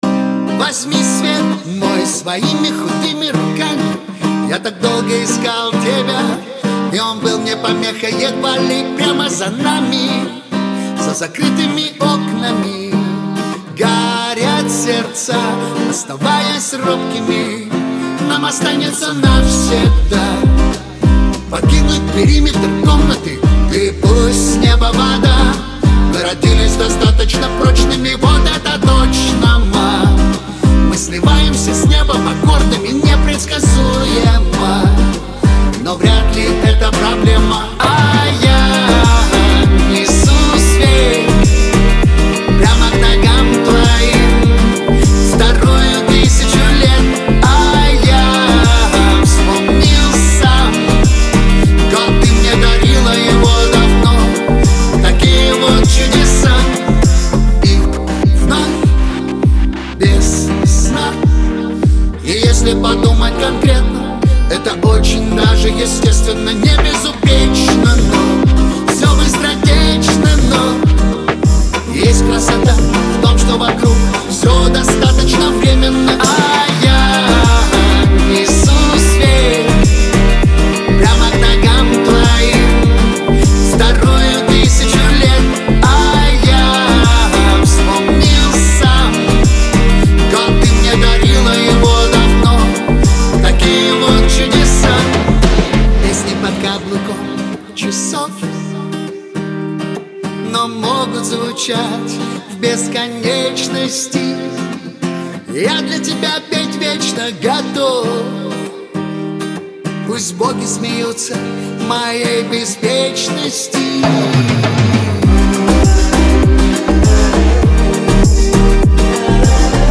Трек размещён в разделе Русские песни / Метал.